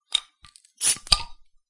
描述：你打开啤酒瓶时的声音。
Tag: 环境 - 声音的研究 啤酒 啤酒瓶 开瓶器